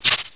1 channel
jump.wav